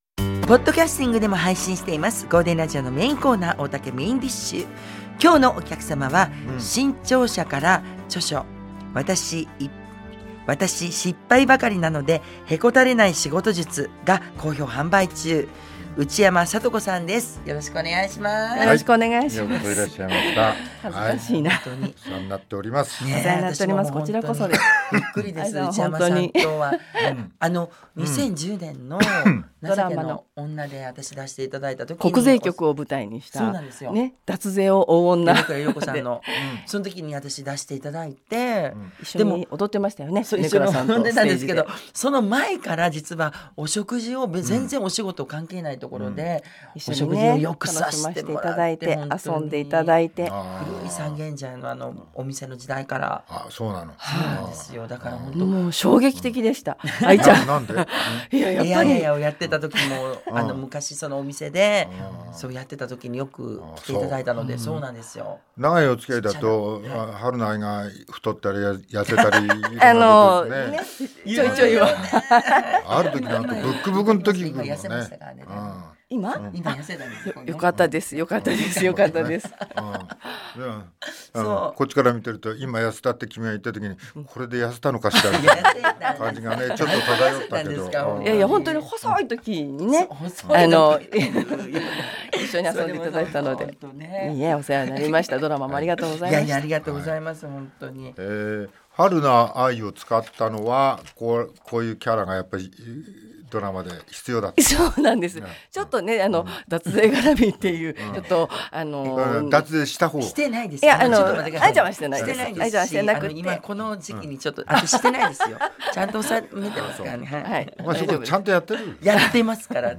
大竹まこと＆各パートナーがお客様のトークを料理します。